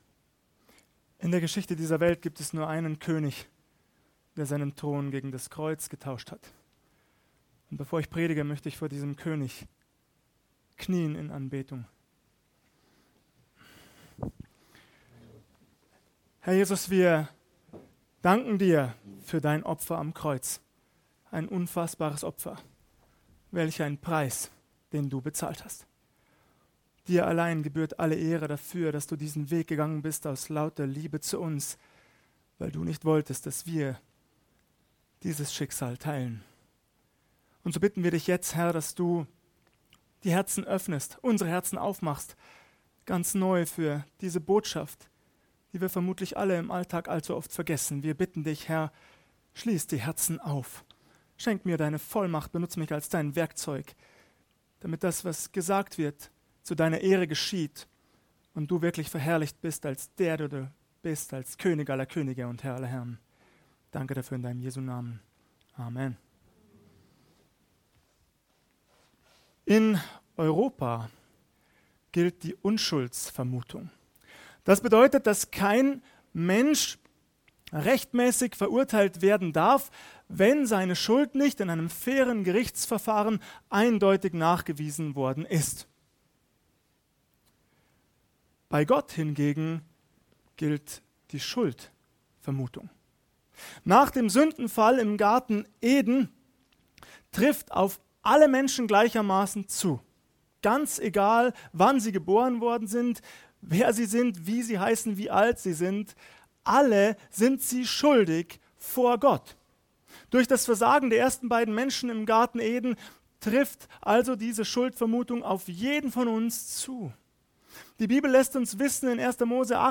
Schuldig vor Gott (Karfreitagsandacht)